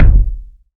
KICK.98.NEPT.wav